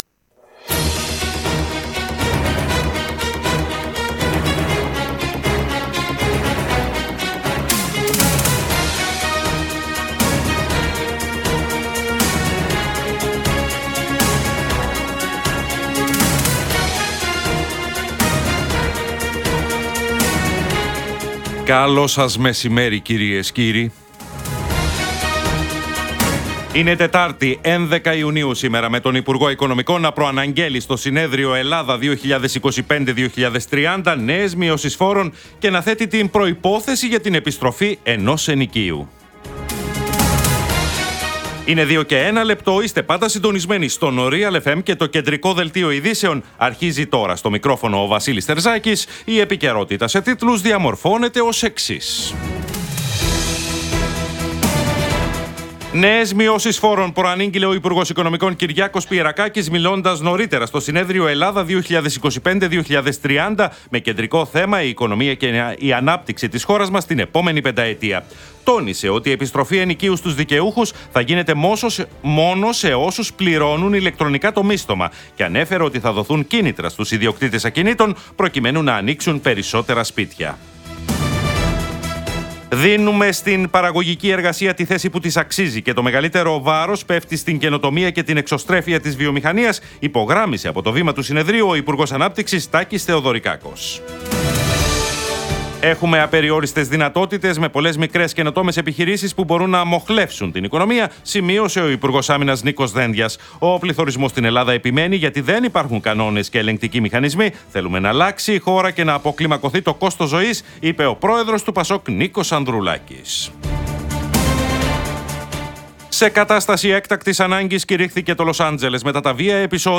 Το κεντρικό δελτίο του Realfm 97,8